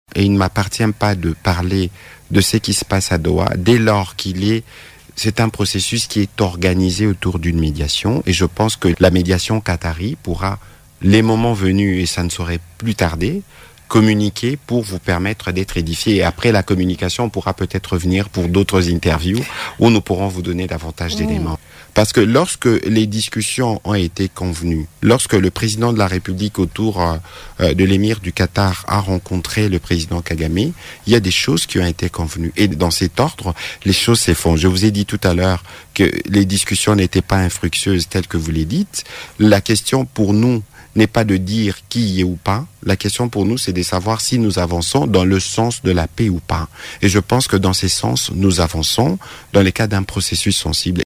Intervenant à l’émission Dialogue entre Congolais de Radio Okapi, le ministre de la Communication et des Médias a assuré que les discussions de Doha avancent dans le sens de la paix.